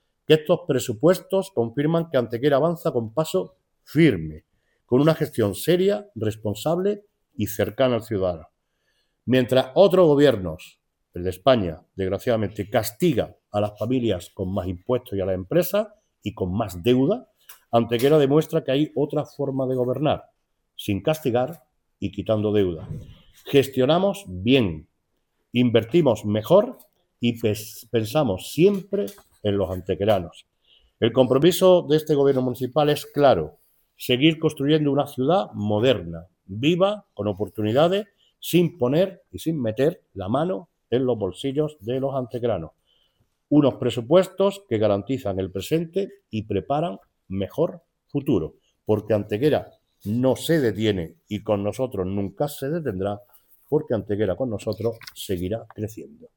El alcalde de Antequera, Manolo Barón, ha presentado hoy ante los medios de comunicación las líneas maestras de los Presupuestos Generales del Ayuntamiento de Antequera para el ejercicio económico 2026, en una rueda de prensa junto al teniente de alcalde delegado de Hacienda, José Manuel Fernández, la portavoz del Equipo de Gobierno, Ana Cebrián, y el resto de integrantes del Equipo de Gobierno del Ayuntamiento de Antequera.
Cortes de voz